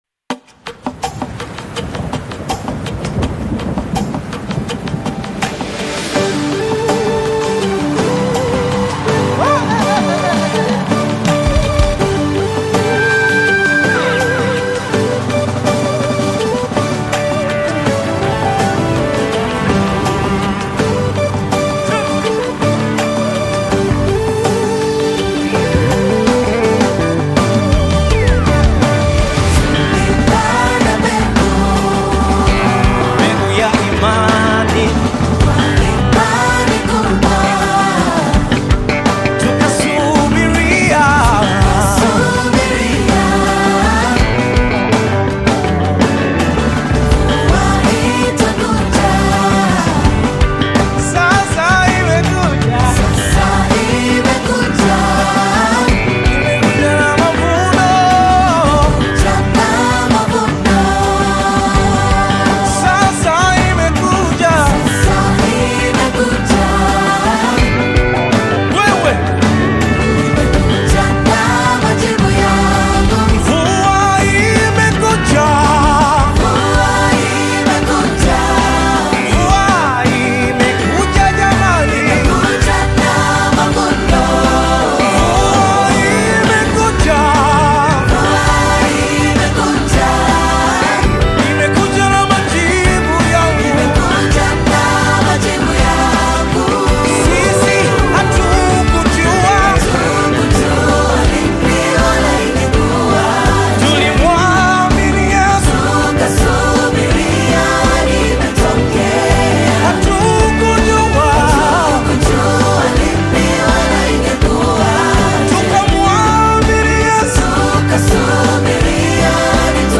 Nyimbo za Dini Worship music
Worship Gospel music track